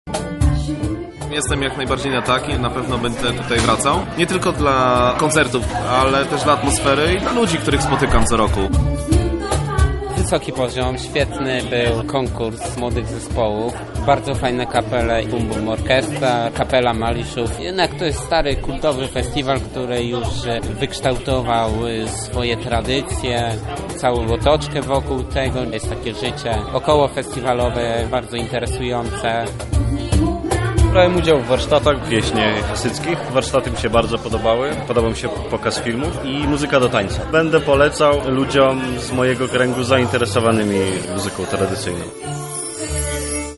mikołajki relacja